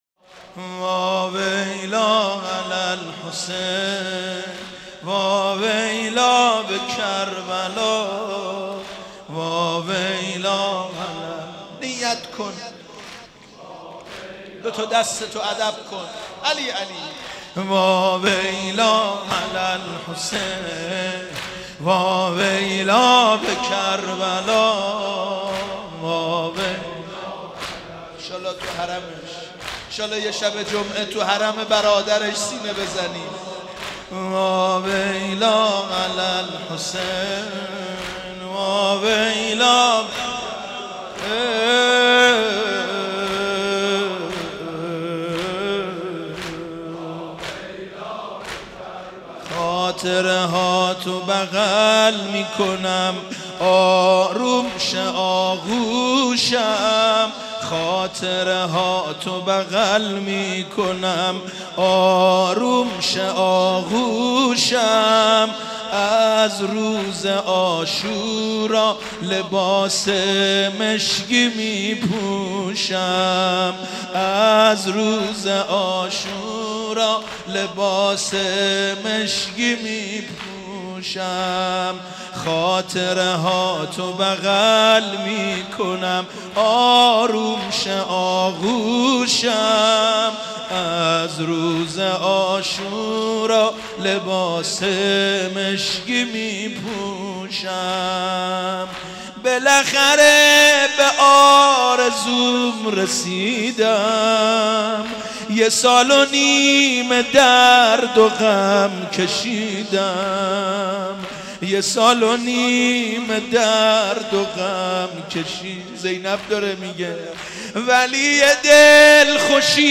مناسبت : وفات حضرت زینب سلام‌الله‌علیها
قالب : زمینه